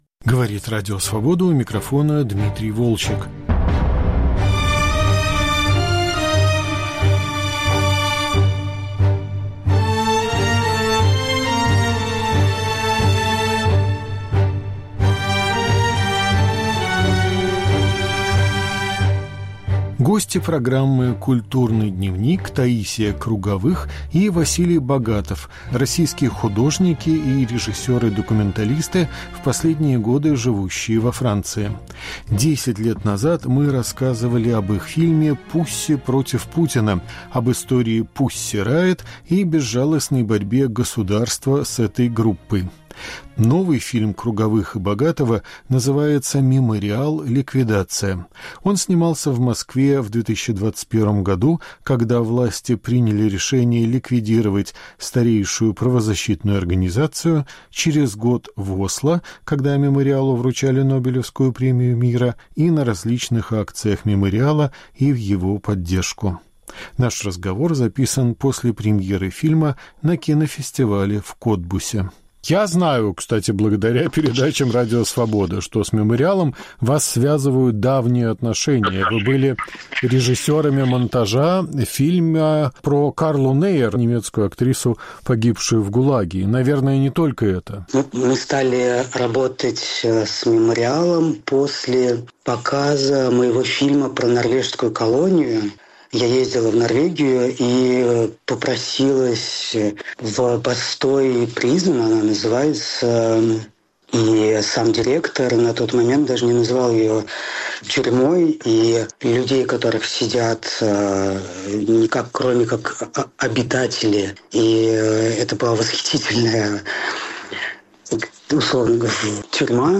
Разговор с документалистами после премьеры фильма «Мемориал/Ликвидация»